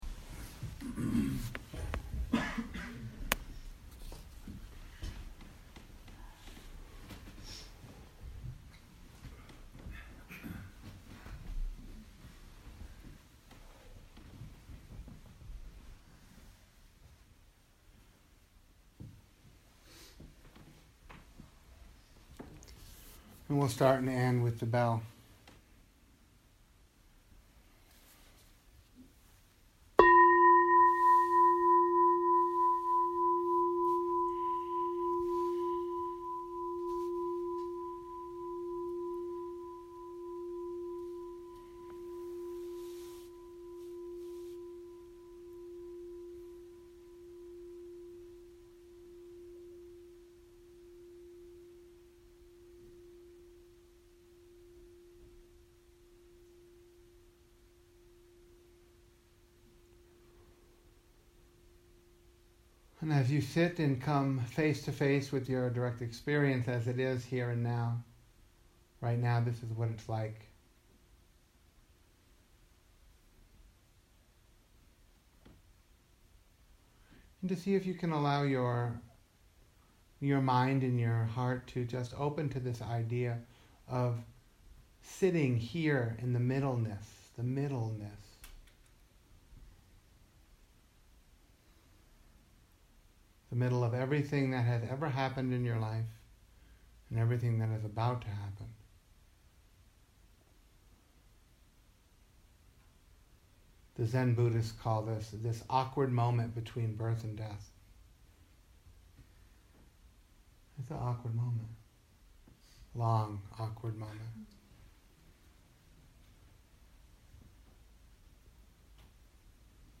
Liberating the heart and mind. GUIDED.